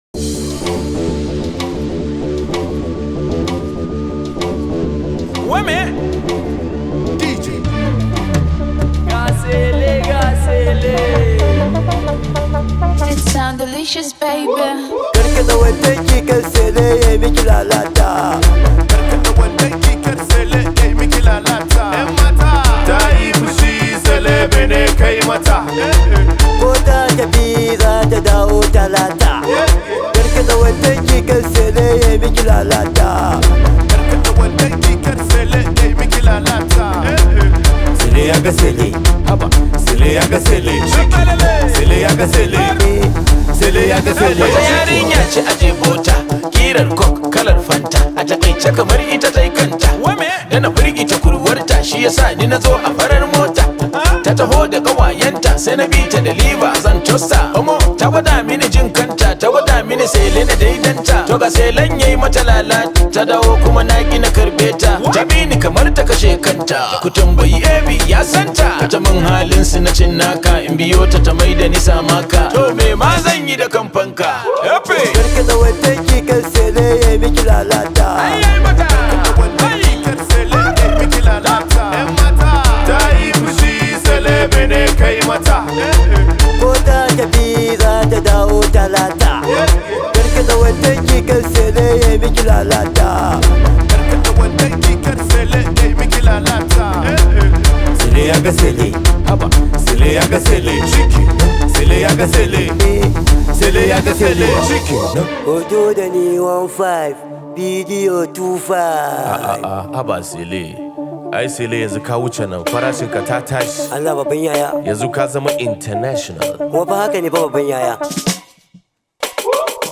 Hausa Singer